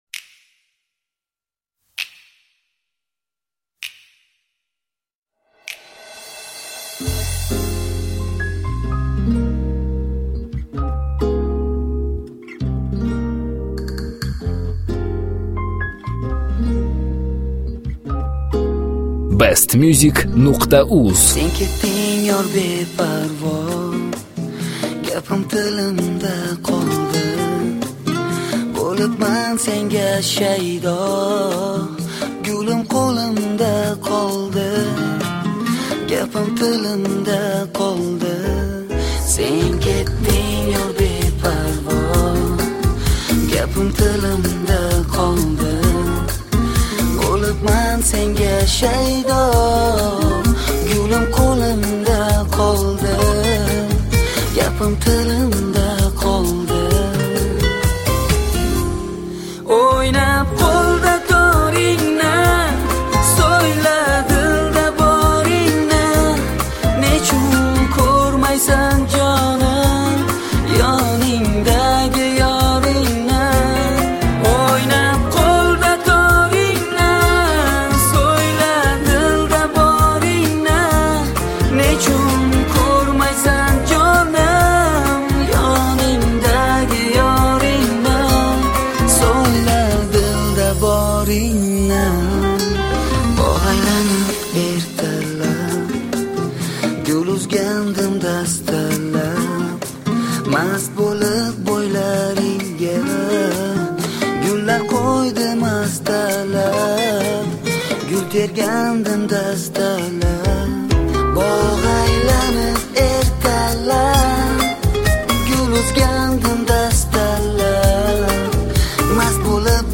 Slow version
Узбекская Музыка